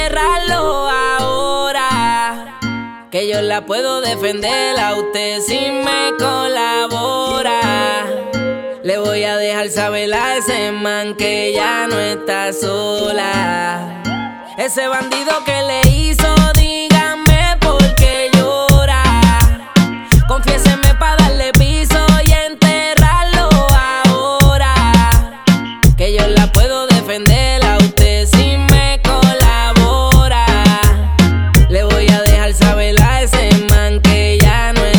# Latin Urban